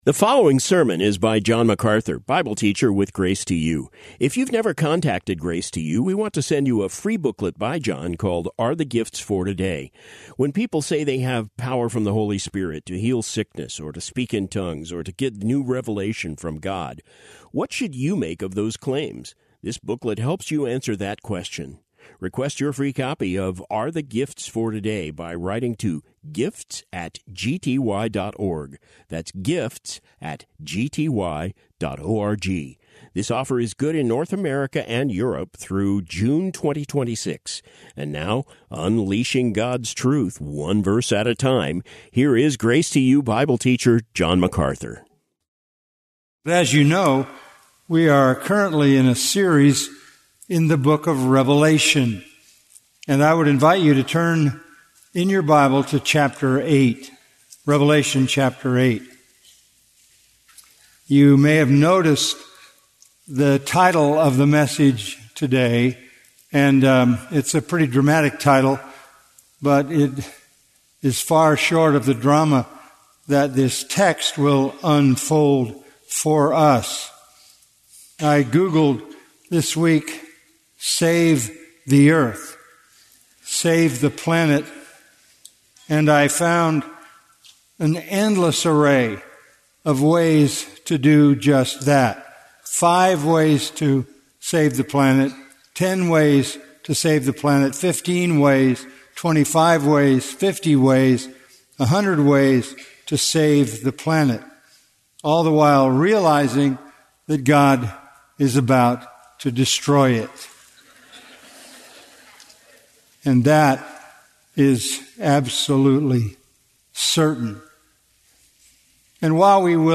SERMON BY JOHN MACARTHUR